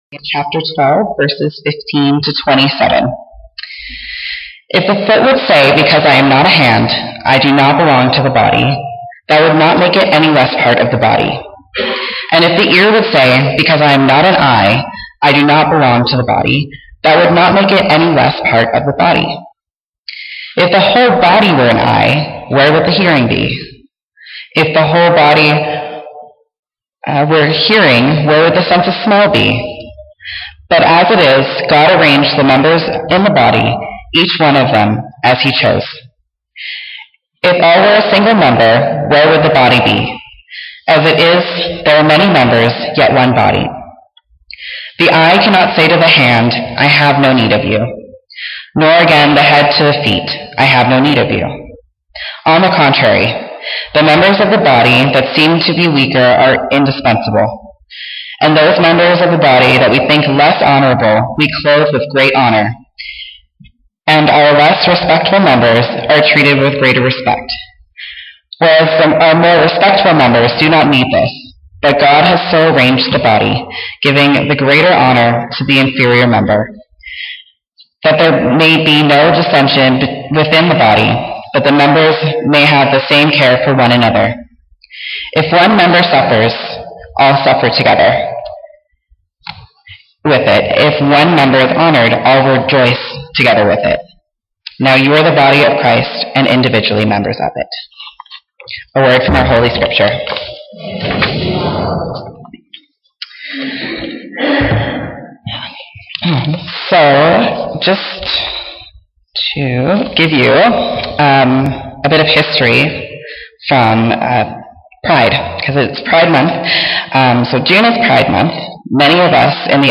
Sermons | Okotoks United Church